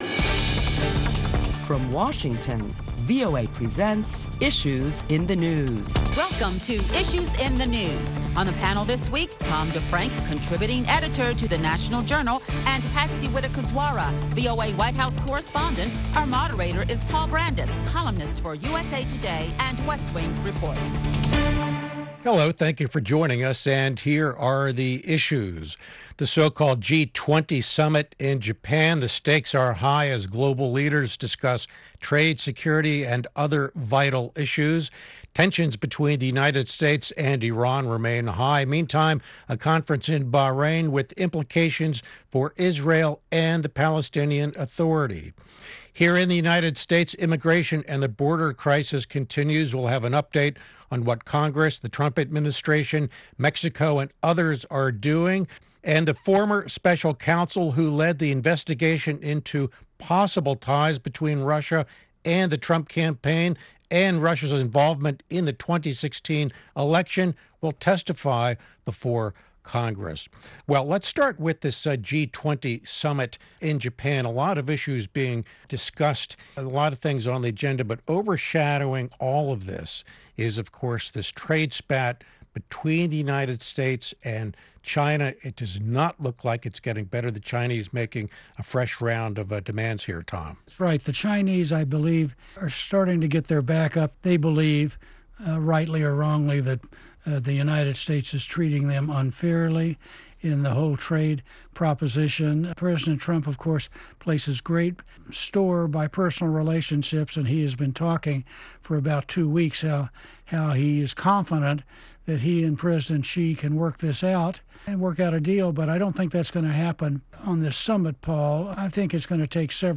Listen to a panel of prominent Washington journalists as they deliberate the week's headlines including President Trump talks with Chinese President Xi Jinping in Osaka, Japan, and the U.S. Senate approves a bipartisan legislation to address the humanitarian crisis along the U-S Mexico border.